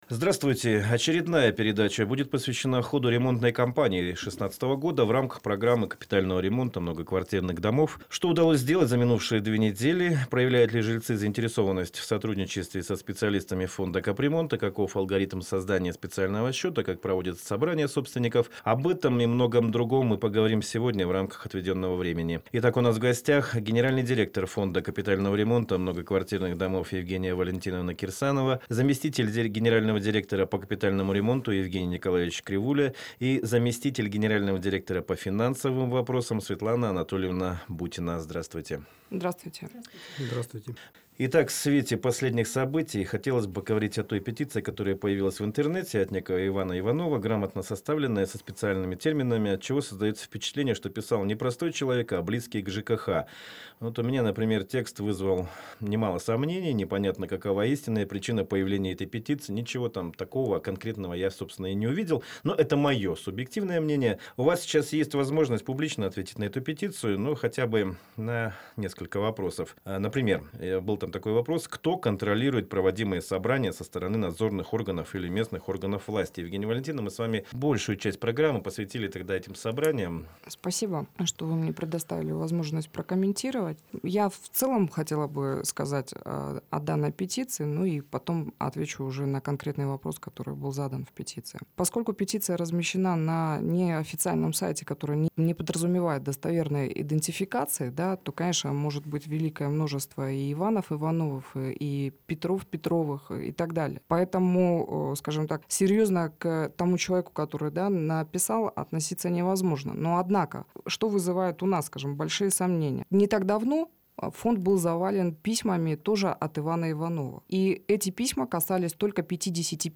ответила на вопросы радиослушателей